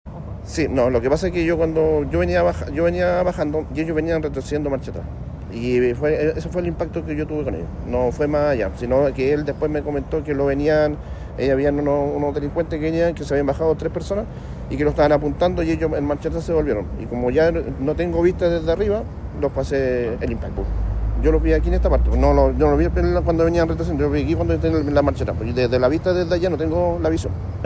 Por su parte, el conductor víctima del delito relató que venía del Aeropuerto de Santiago cuando se produjo la encerrona.